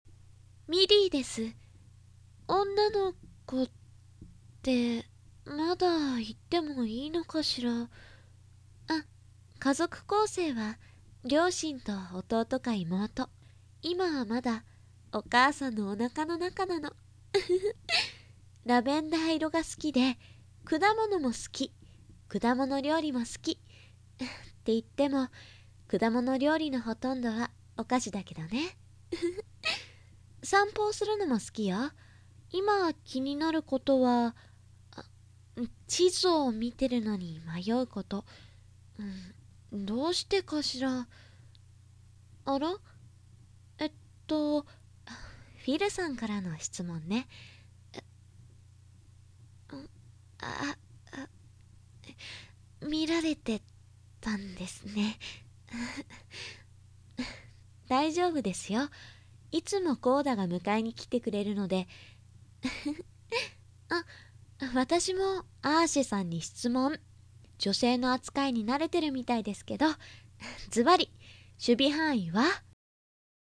１０代後半/女性
サンプルボイス